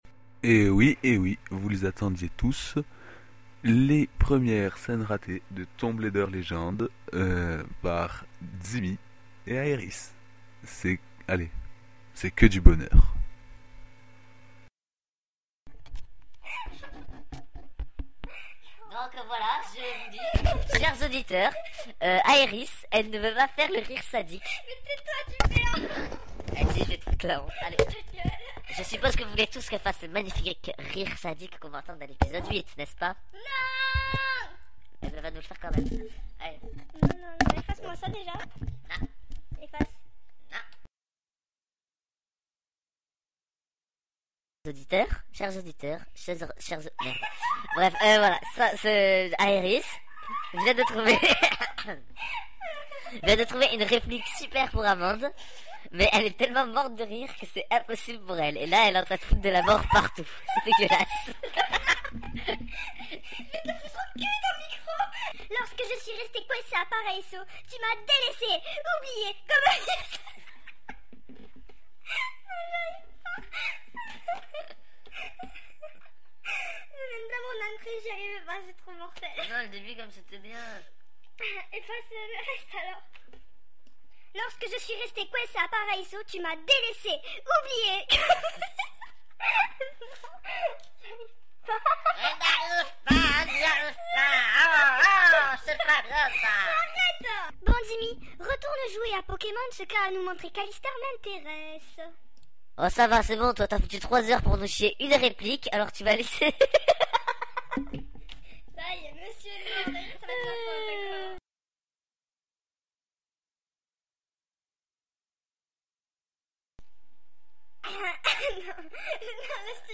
bordel complet, mais on rigole!!!!